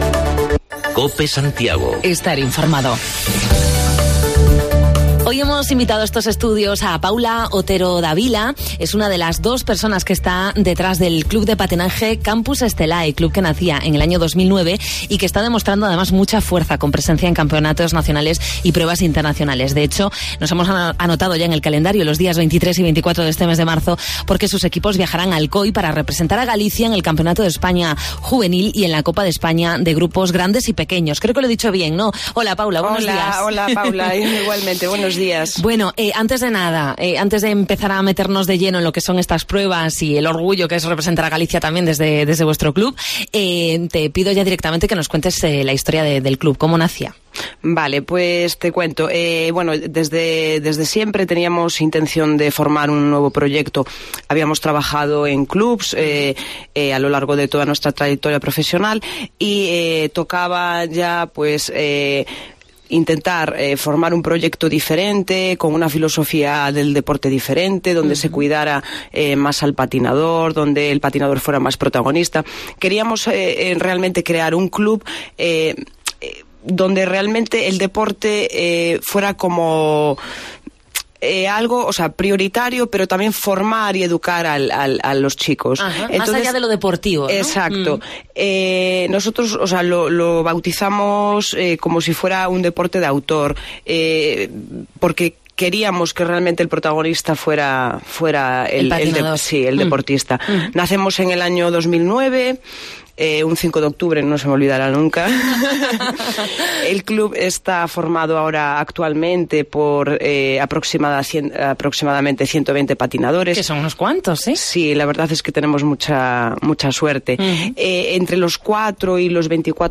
Entrevista al Club Campus Stellae